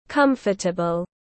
Dễ chịu tiếng anh gọi là comfortable, phiên âm tiếng anh đọc là /ˈkʌm.fə.tə.bəl/
Comfortable /ˈkʌm.fə.tə.bəl/